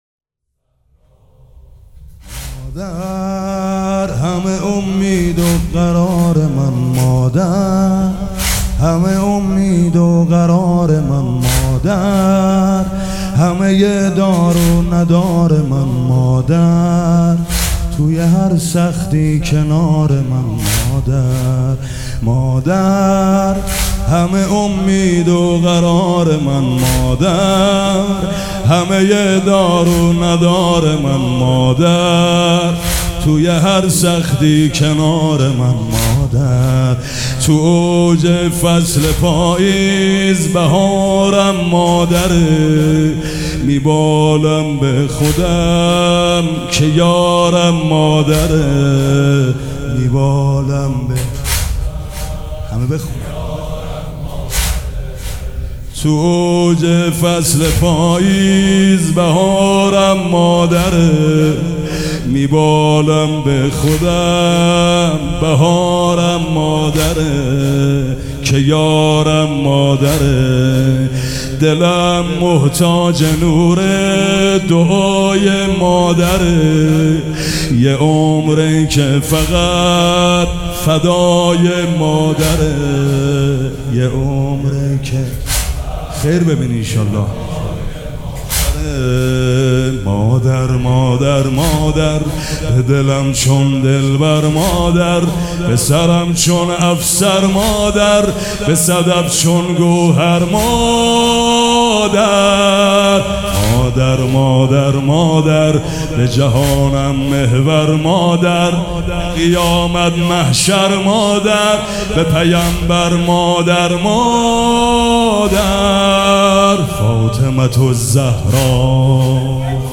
مداح
مراسم عزاداری شب شهادت حضرت زهرا (س)